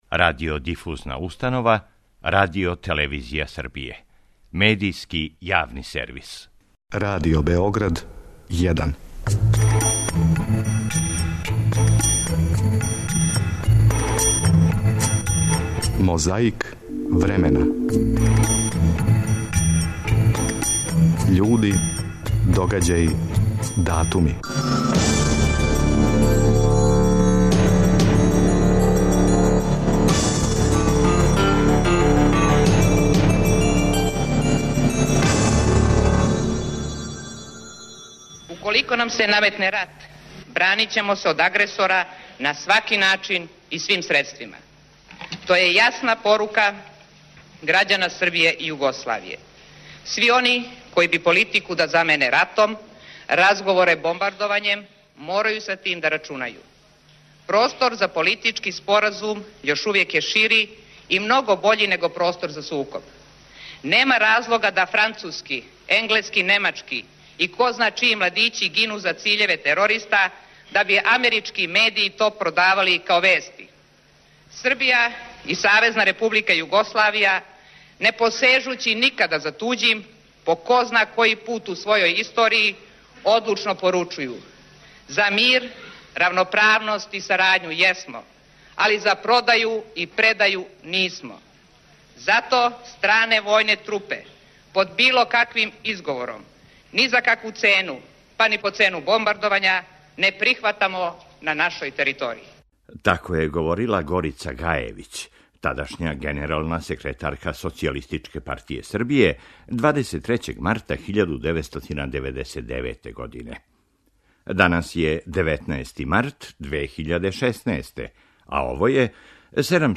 На повратку у домовину одржао је говор, на нематерњем језику.